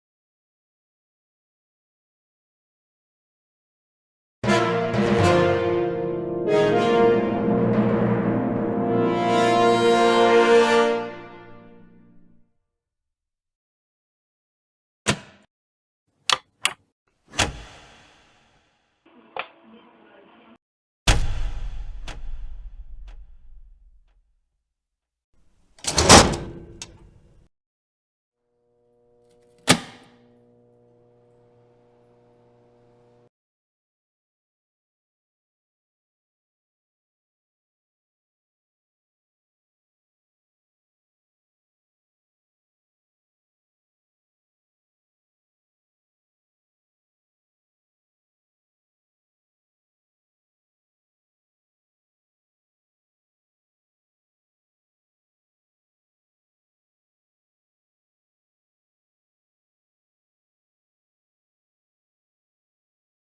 Big Switch Sound Effects All Sounds.mp3